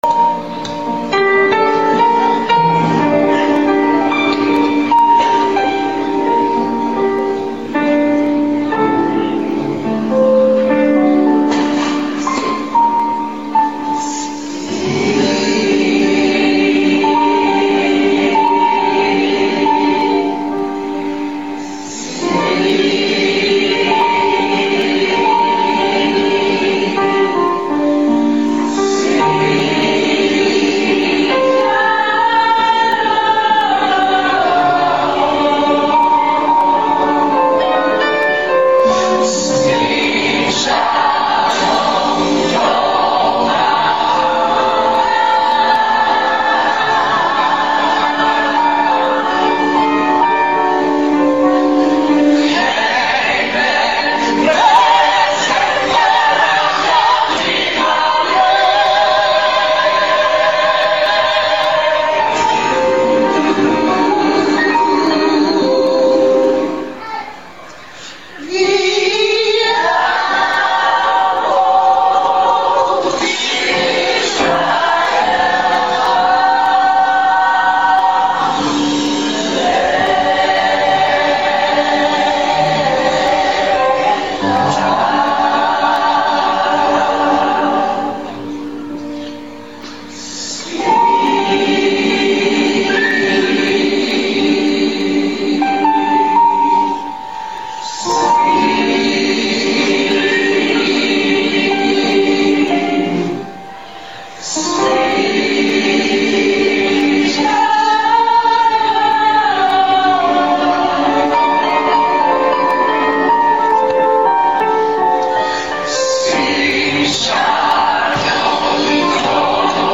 The program for the evening was an eclectic blend of songs taken from the traditional synagogue liturgy and sung in impeccable Hebrew to those that are associated with the musical genre called “Negro Spirituals.”
The hundreds of white and black people who packed into Tarble Hall that evening felt the common Ruach (spirit) that brought the audience to their feet and had them clapping, swaying, and dancing at several points during the concert.
Sim Shalom Beth El Swarthmore Concert 06.mp3